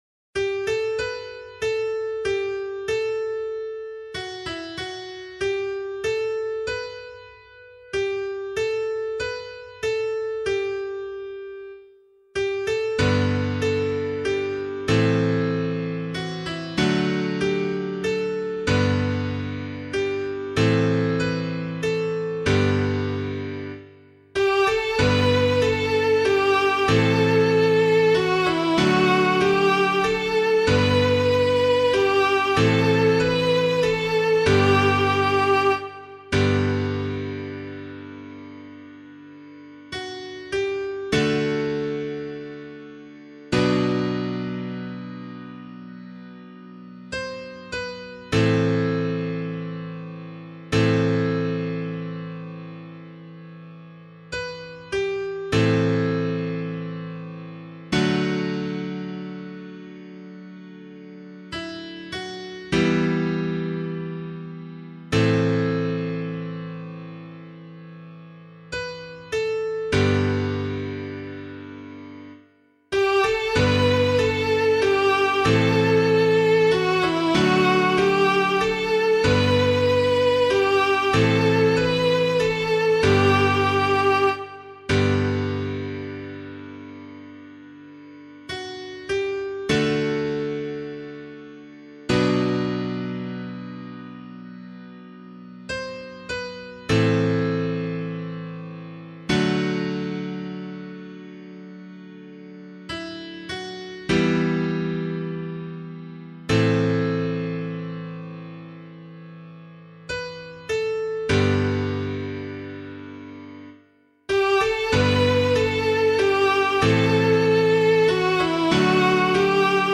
022 Easter Vigil Psalm 3 [LiturgyShare 7 - Oz] - piano.mp3